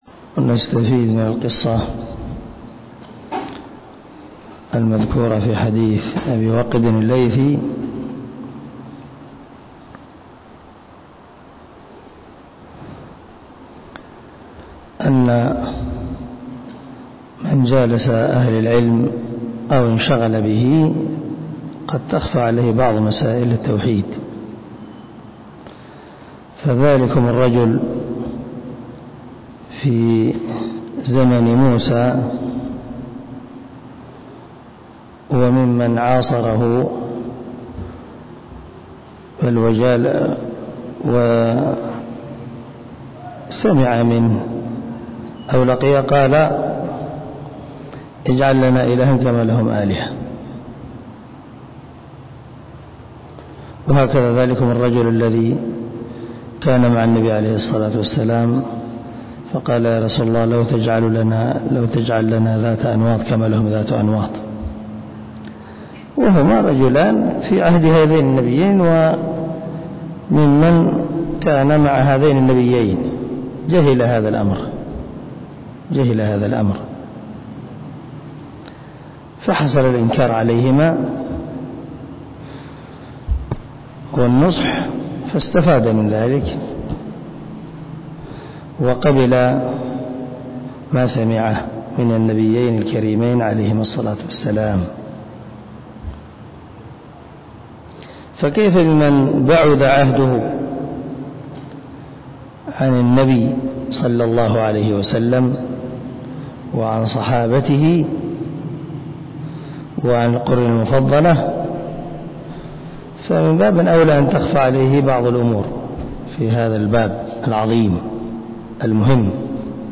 شرح كشف الشبهات 0019 الدرس 18 من شرح كتاب كشف الشبهات